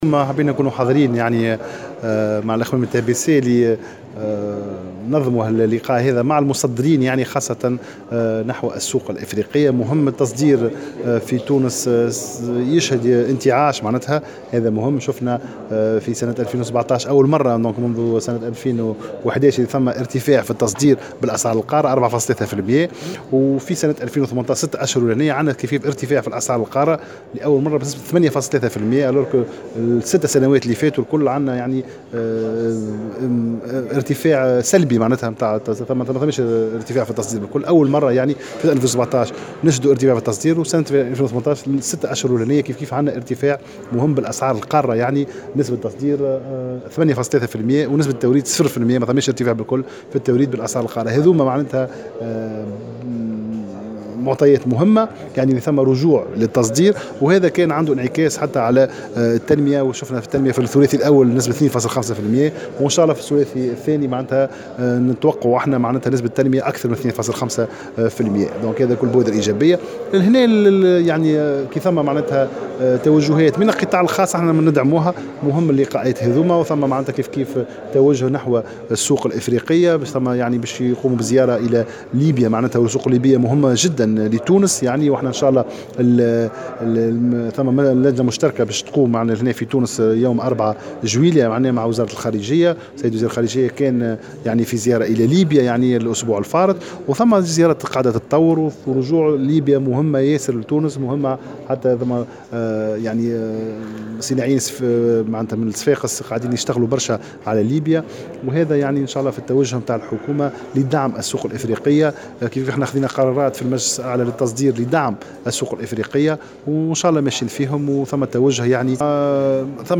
وقال في تصريح لمراسل "الجوهرة أف أم" على هامش منتدى اقتصادي حول دفع التصدير نحو الأسواق الافريقية،إنه تم سجيل زيادة بـ 8.3 % بالأسعار القارة للصادرات التونسية في الأشهر الستة الأولى من السنة الحالية دون زيادة على مستوى التوريد، بحسب تعبيره، متوقعا تحقيق نمو بأكثر من 2.5% نهاية العام الحالي.